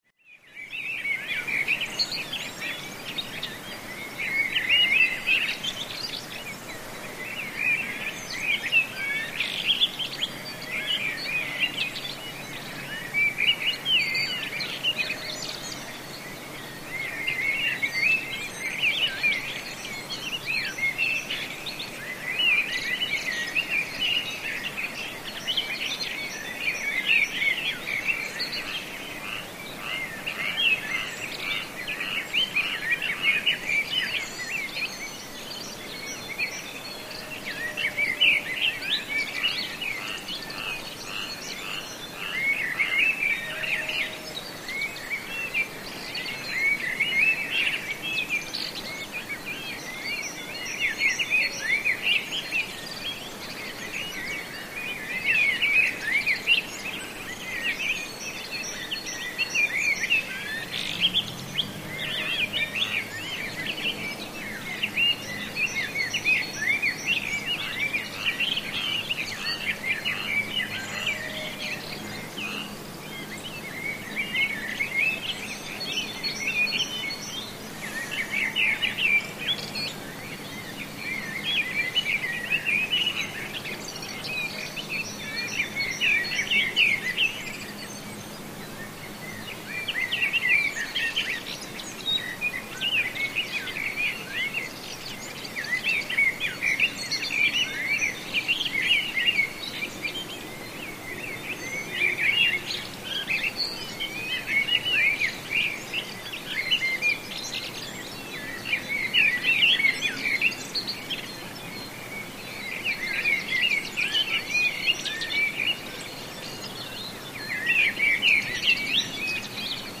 Early Morning Birds And Frogs, Occasional Sheep In Distance